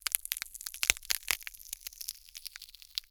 ALIEN_Insect_19_mono.wav